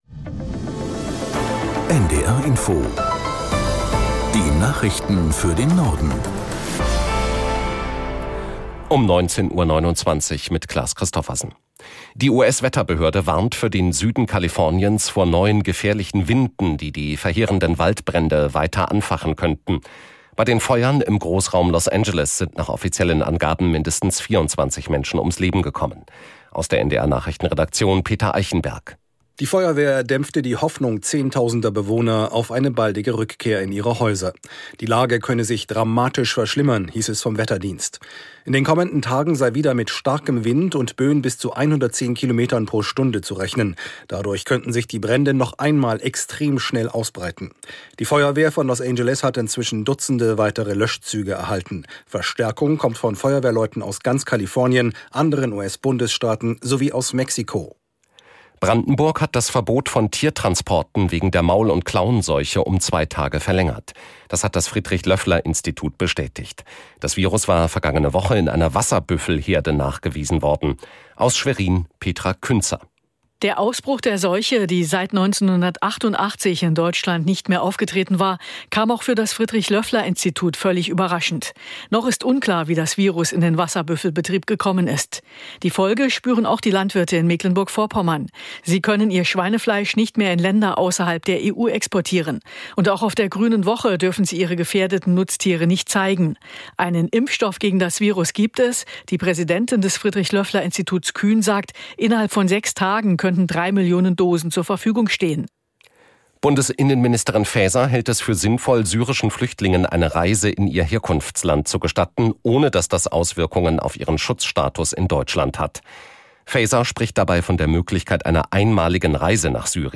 Nachrichten für den Norden.